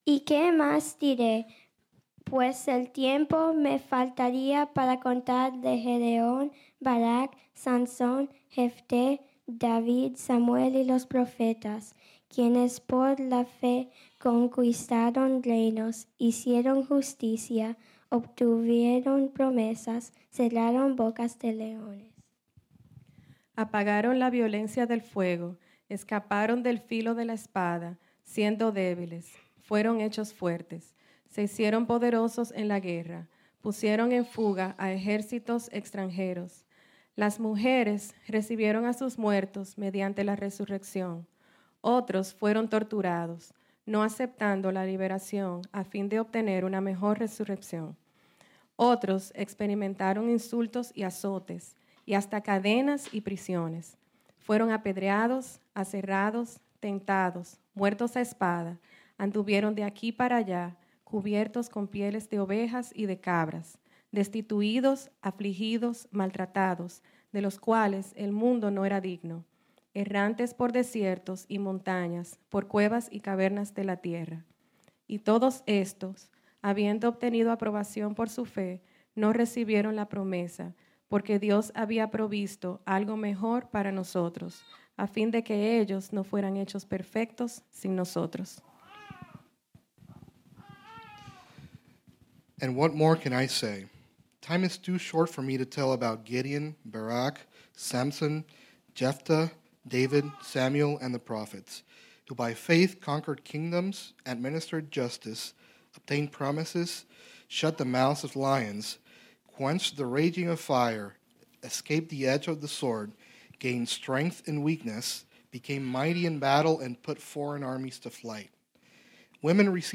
This sermon was originally preached on Sunday, February 12, 2023.